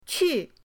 qu4.mp3